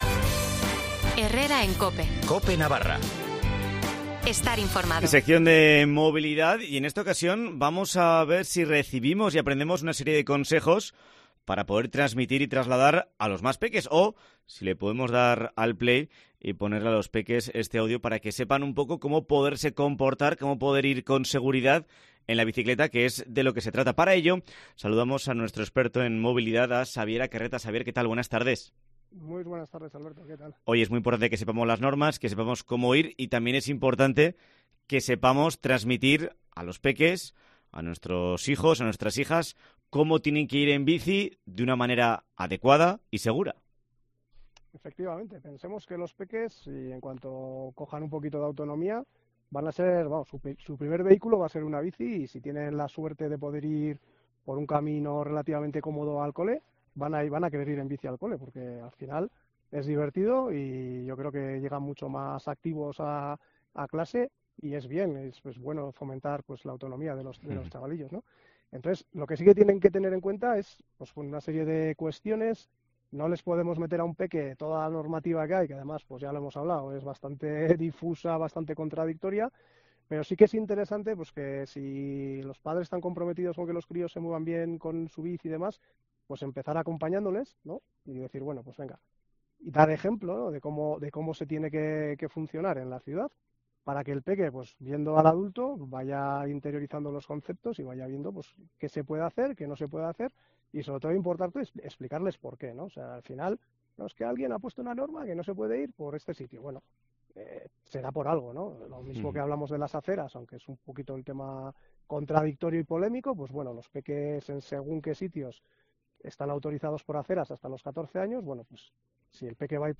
experto en movilidad, dice en COPE Navarra unas claves que tenemos que tener en cuenta. Nos deja unas pautas importantes para que un menor de edad se mueva en bicicleta por la ciudad: velocidad, distancia, elección de la ruta, el ejemplo de los adultos y llevar luces.